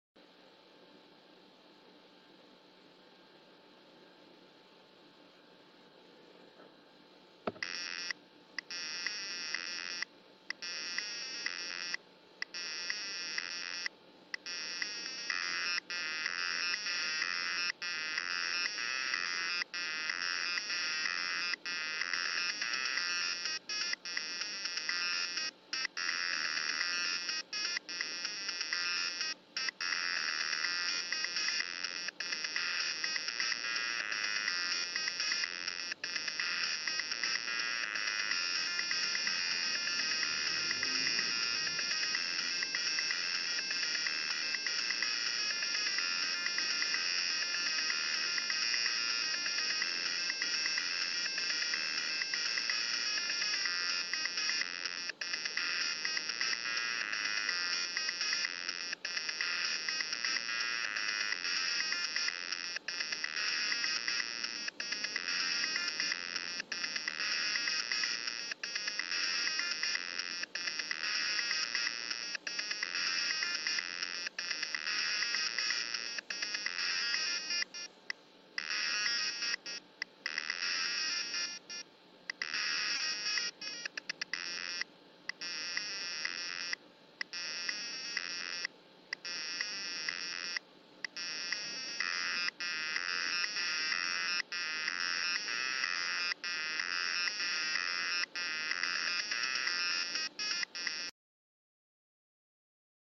Let's hear the music capabilities of a 1982 Sinclair ZX Spectrum.